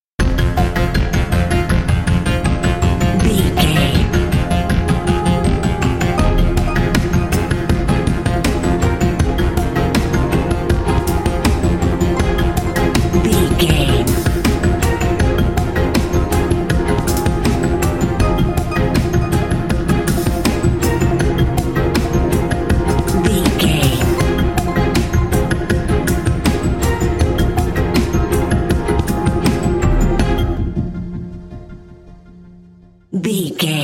Aeolian/Minor
synthesiser
tension
ominous
dark
suspense
haunting
tense
creepy
spooky